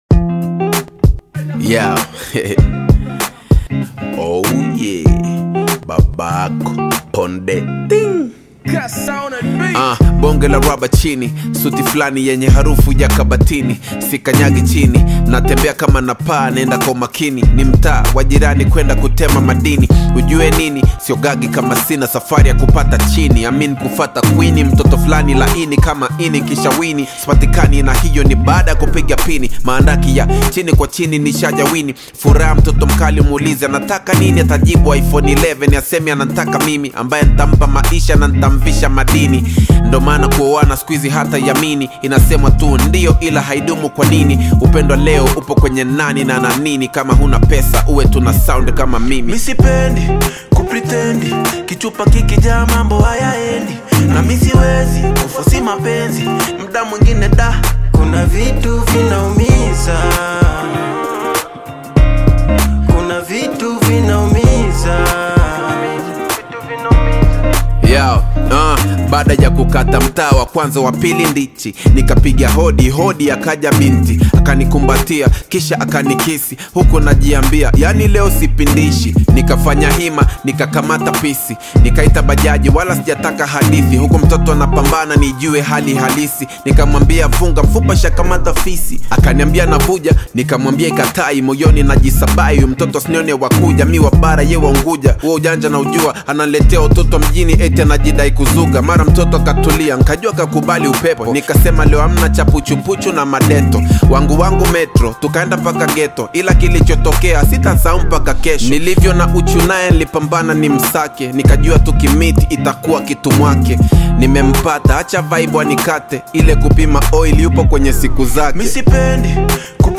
Tanzanian Afro-Hip-Hop/Afrobeats single
delivering raw lyrical emotion and rhythmic depth.